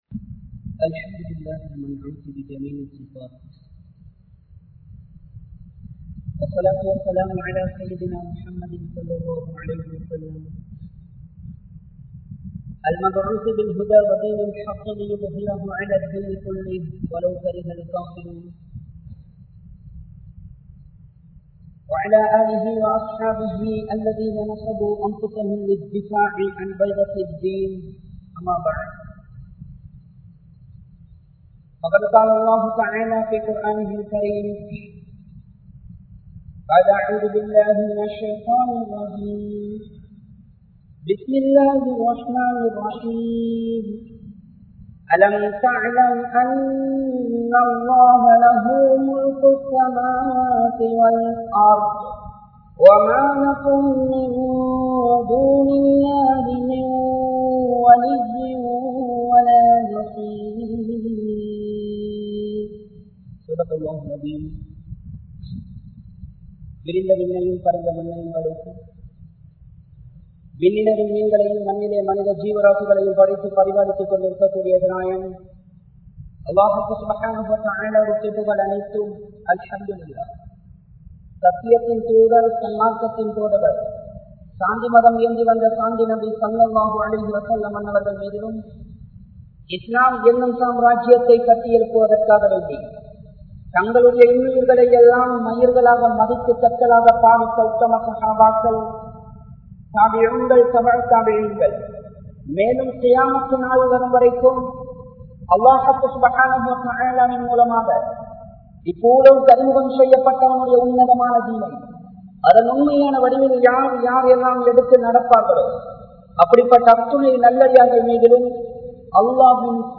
Aatsien Sonthakaaran Allah (ஆட்சியின் சொந்தக்காரன் அல்லாஹ்) | Audio Bayans | All Ceylon Muslim Youth Community | Addalaichenai